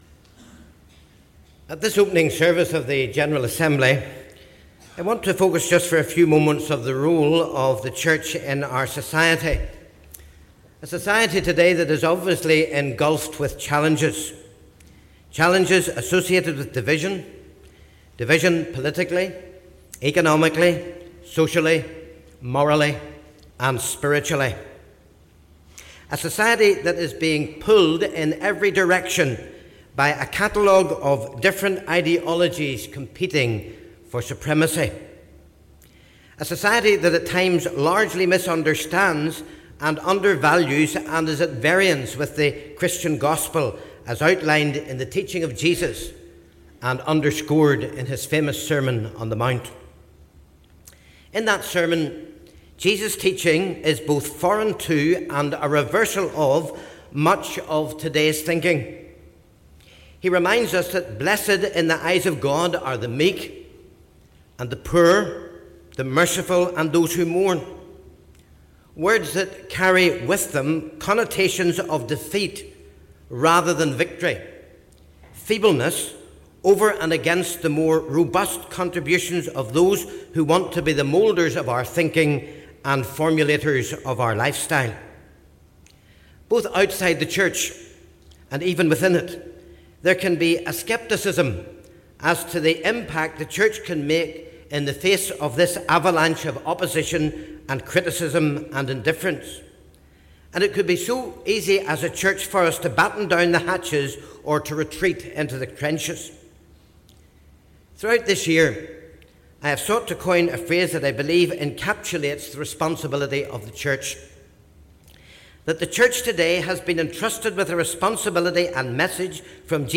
Address of the outgoing Moderator, Dr. Ian McNie, to the Opening Meeting of the 2016 General Assembly.
The Assembly met in Assembly Buildings, Belfast from Monday, 6th June until Friday, 10th June, 2016.